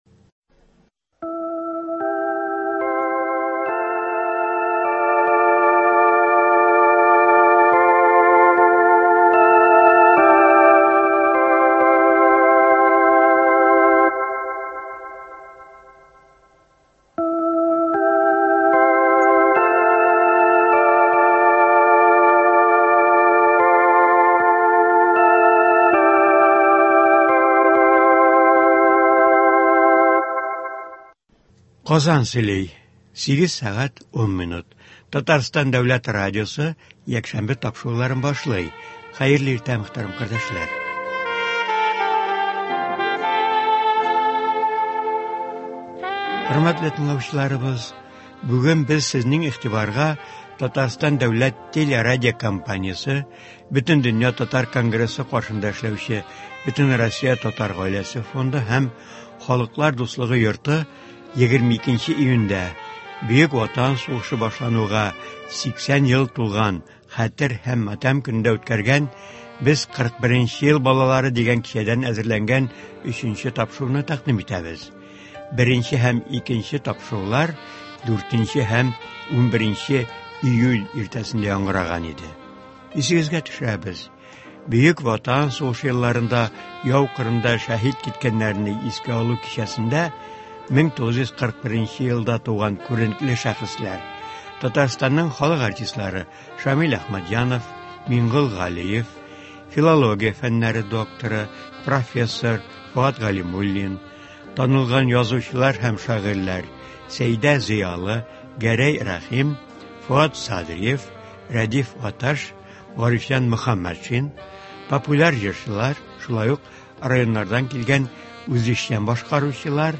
22 июньдә, Бөек Ватан сугышы башланган Хәтер һәм матәм көнендә, ил күләмендә төрле чаралар үткәрелде. Татарстан дәүләт телерадиокомпаниясе Бөтендөнья татар конгрессы каршында эшләүче Бөтенроссия Татар гаиләсе фонды белән берлектә “Халыклар дуслыгы” йорты ярдәме белән “Без 41 ел балалары” дигән искә алу кичәсе үткәрде. Анда 1941 елда туган күренекле шәхесләр, сәнгать осталары катнашты.